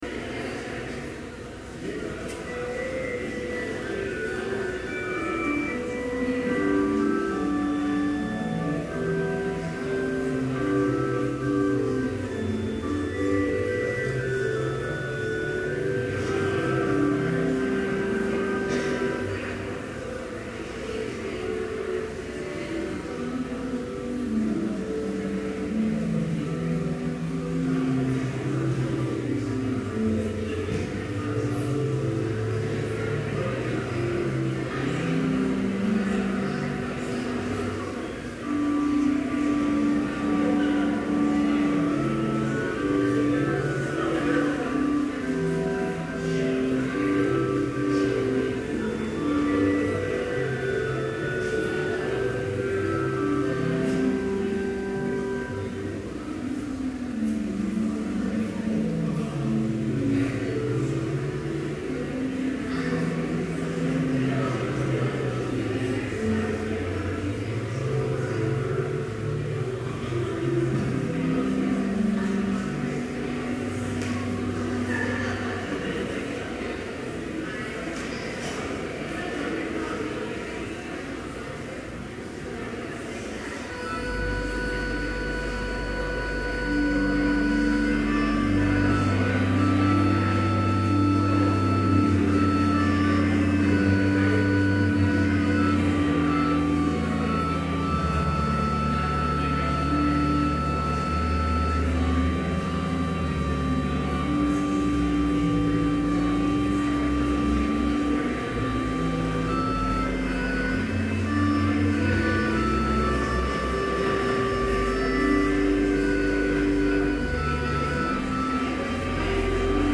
Complete service audio for Chapel - September 15, 2011
Prelude Hymn 1, vv. 1-3, Blessed Jesus, at Thy Word Scripture Reading: I Corinthians 2:9-12 NIV Homily Prayer Hymn 1, v. 4, Father, Son and Holy Ghost... Benediction Postlude